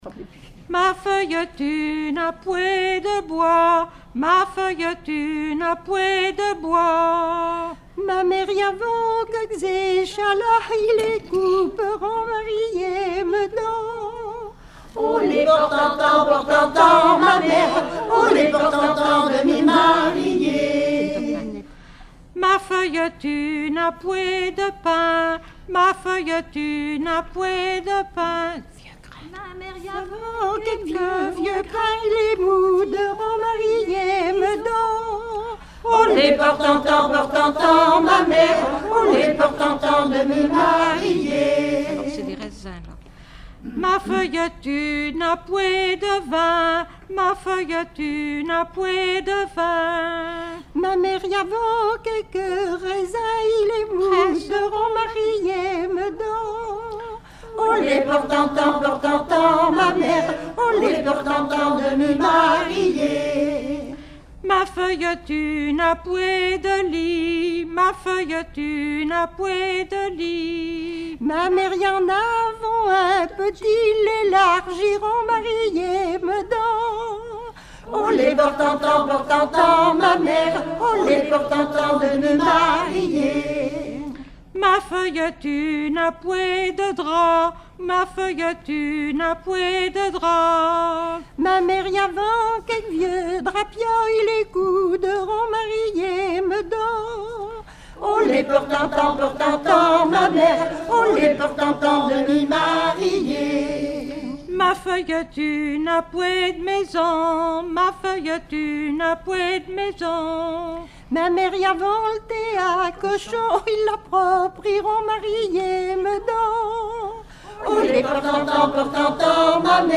Mémoires et Patrimoines vivants - RaddO est une base de données d'archives iconographiques et sonores.
Dialogue mère-fille
Regroupement de chanteurs du canton
Pièce musicale inédite